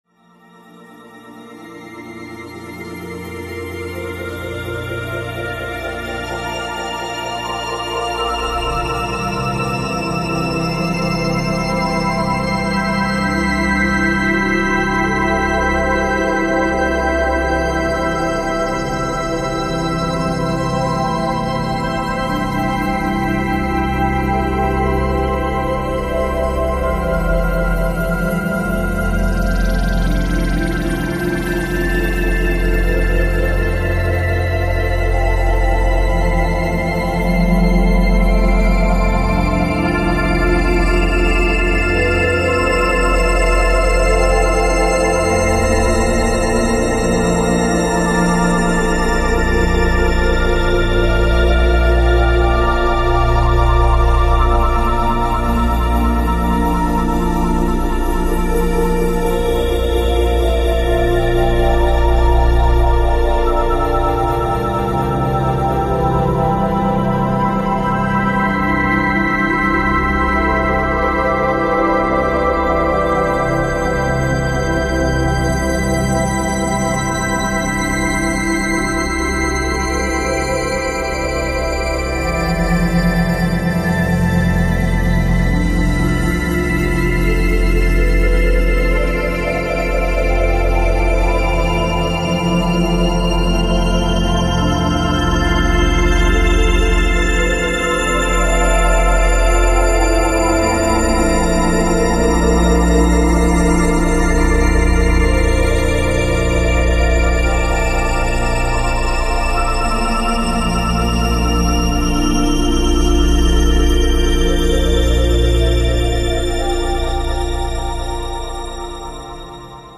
Listen to the relaxing, swirling sounds.